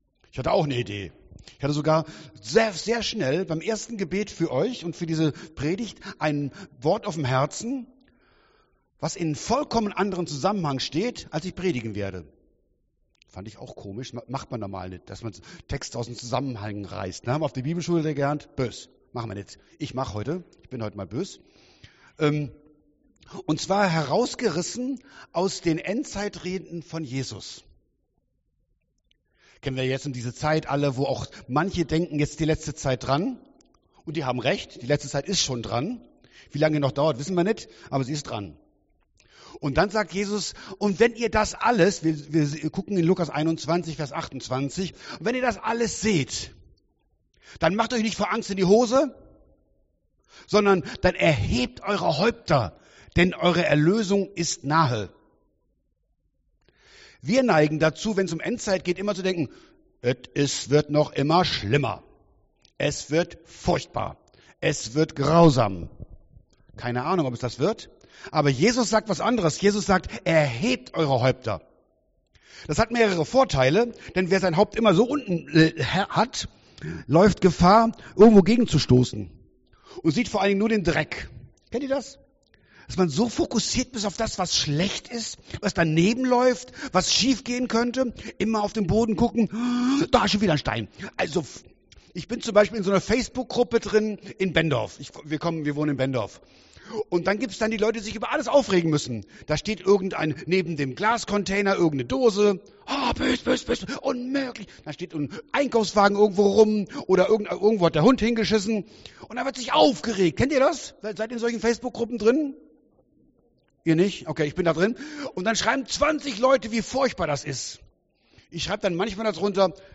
Predigt 06.02.2022